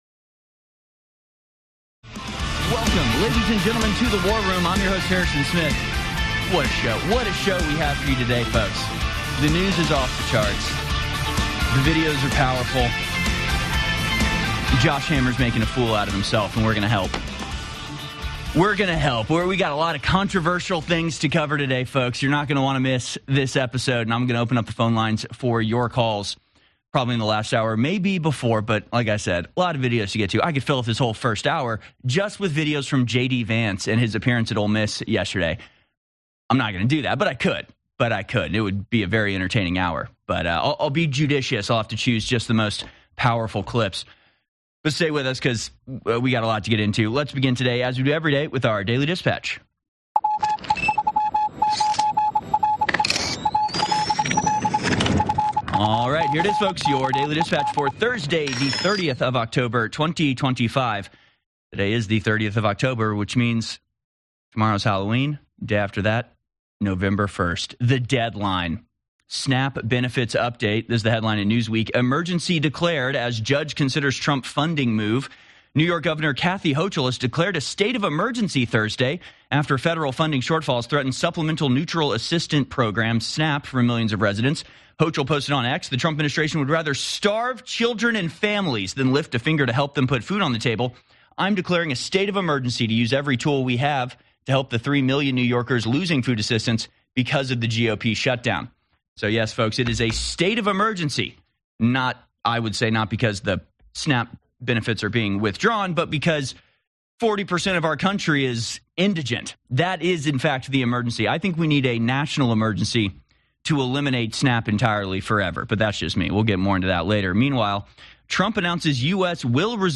Alex Jones and Infowars Shows Commercial Free